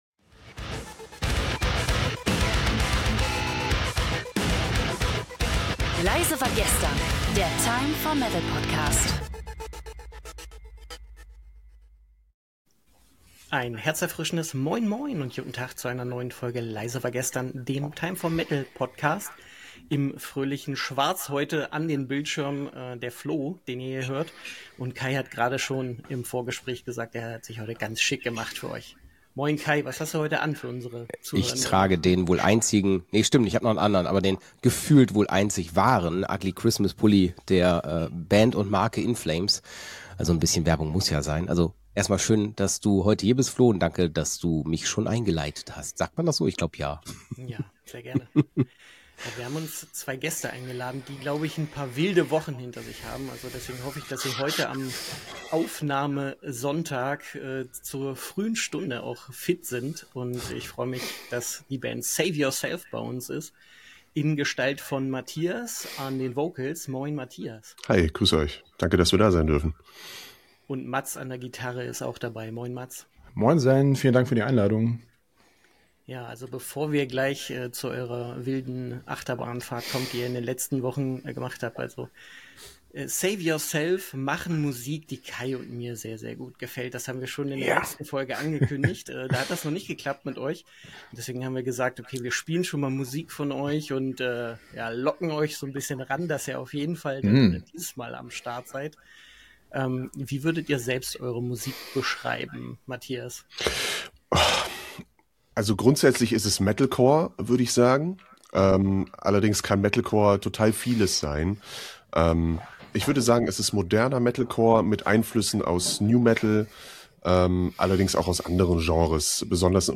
Neben inhaltlicher Tiefe sprechen die Hosts mit der Band über ihren neuen Deal bei Corrupted Blood Records , die Herausforderungen rund um Bandnamen, kreative Phasen im Songwriting und Inspirationen aus Gaming, Film und persönlichen Erfahrungen.